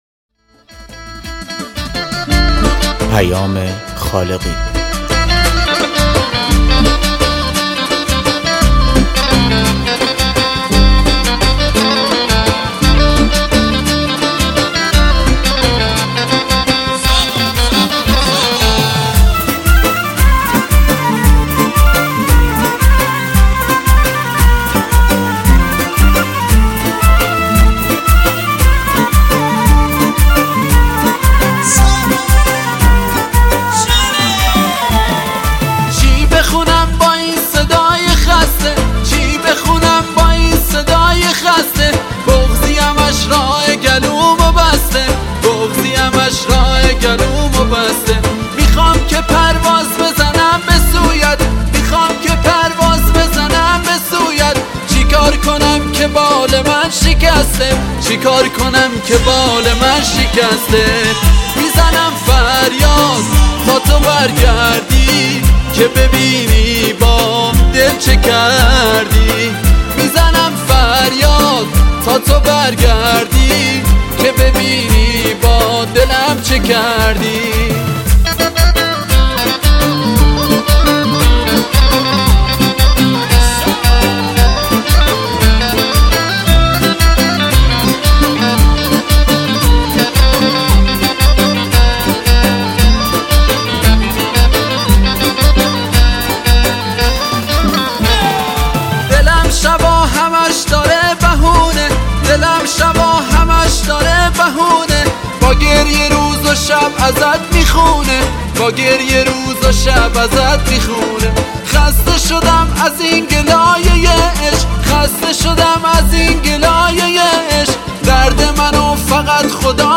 آهنگ مشهدی